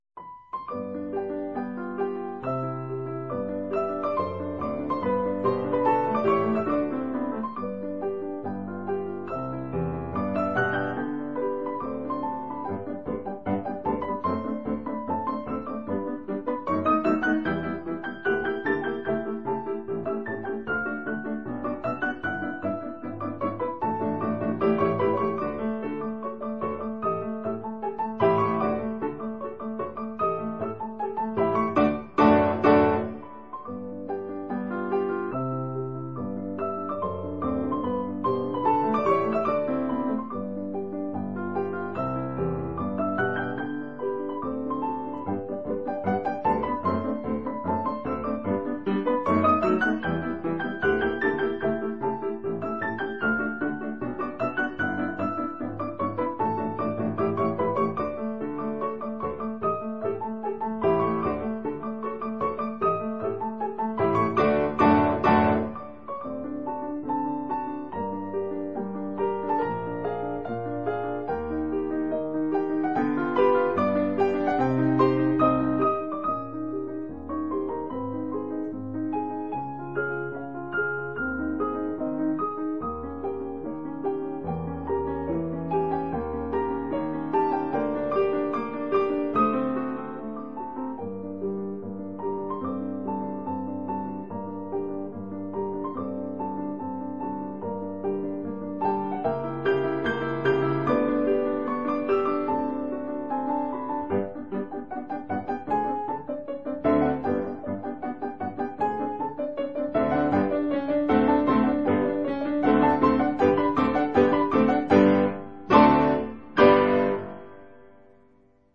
鋼琴四手聯彈